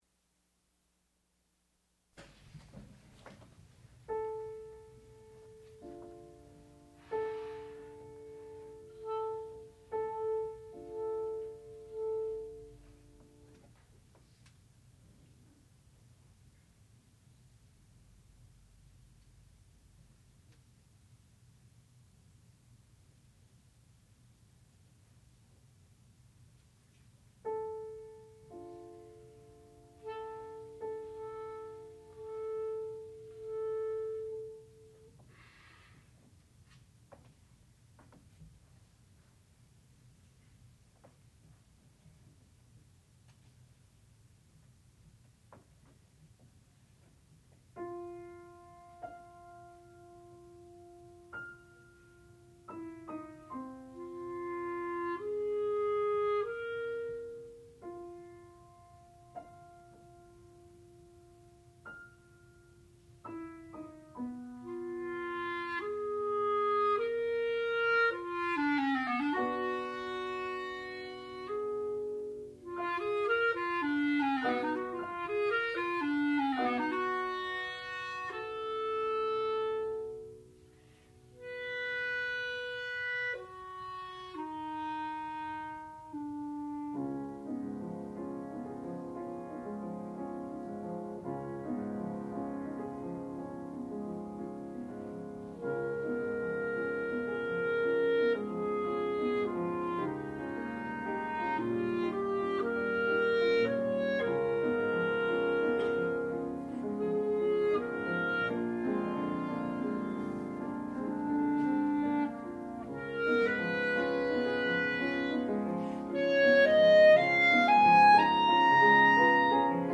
sound recording-musical
classical music
Master's Recital
clarinet